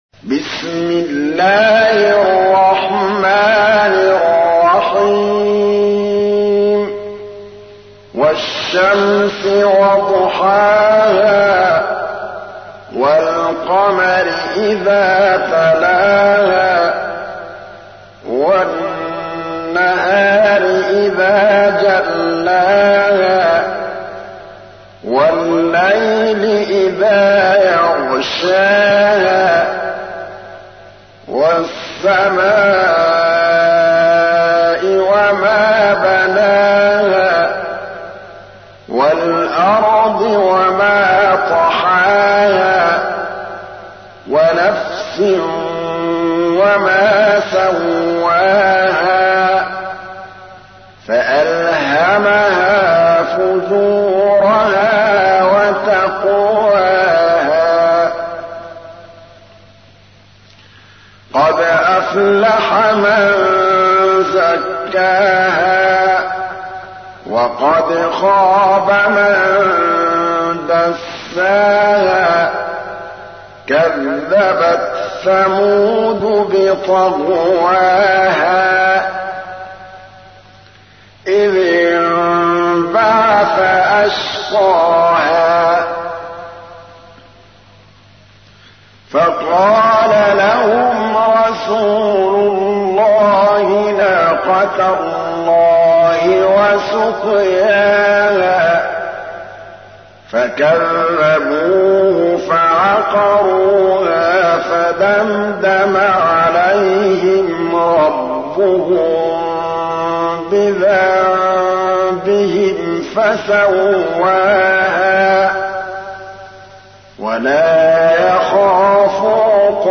تحميل : 91. سورة الشمس / القارئ محمود الطبلاوي / القرآن الكريم / موقع يا حسين